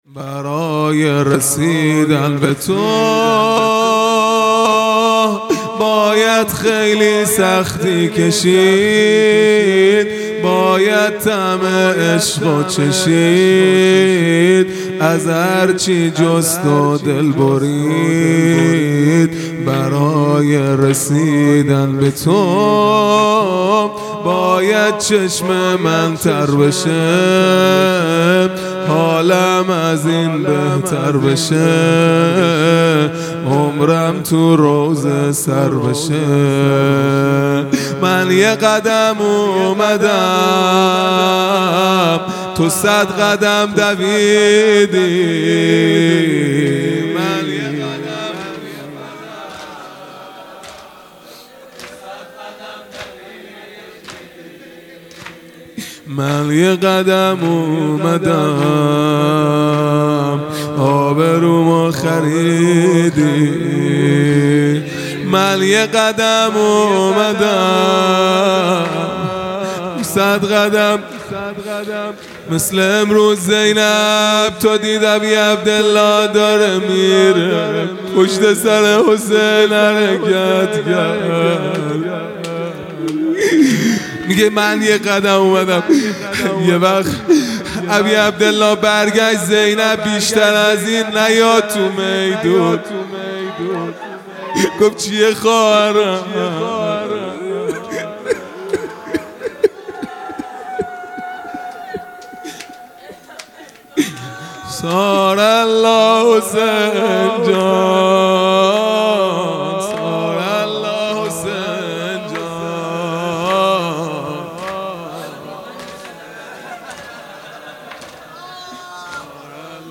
زمینه | برای رسیدن به تو باید خیلی سختی کشید | دوشنبه ۱۷ مرداد ماه ۱۴۰۱
محرم الحرام ۱۴۴۴ | صبح عاشورا | دوشنبه ۱۷ مرداد ماه ۱۴۰۱